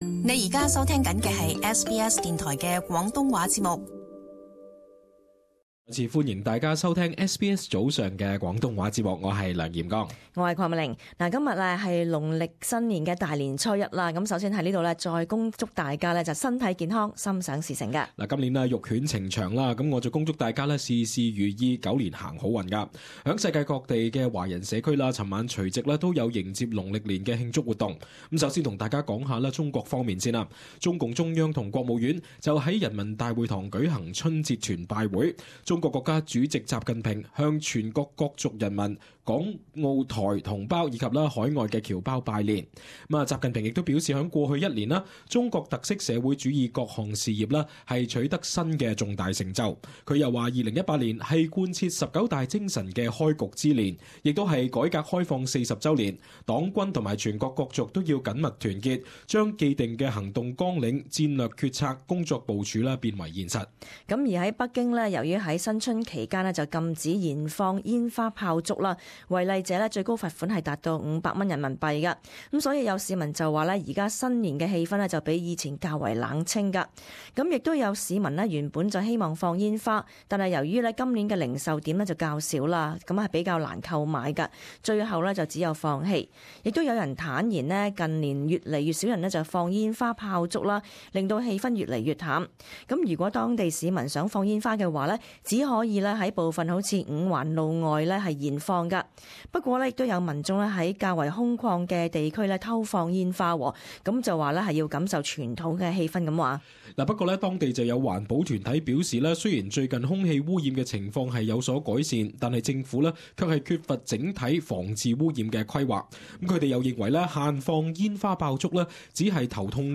【時事報導】亞洲各地慶祝農曆新年